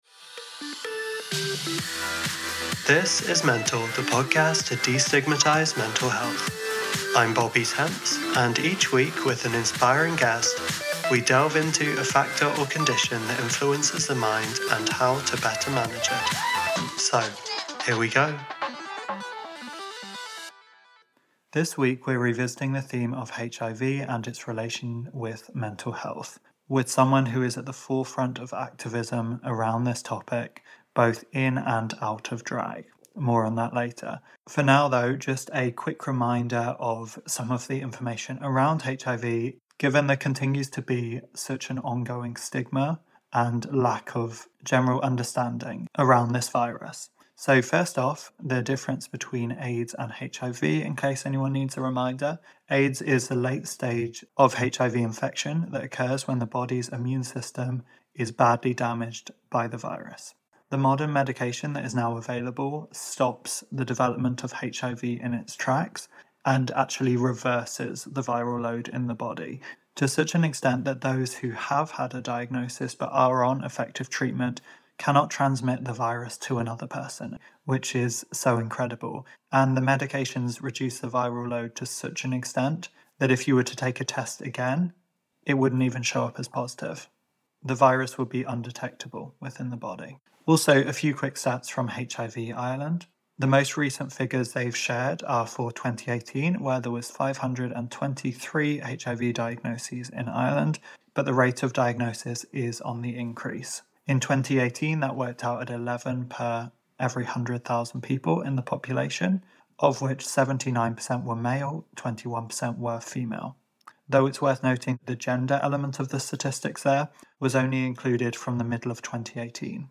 We also delve into depression, sexuality, gender and so much more in this packed conversation.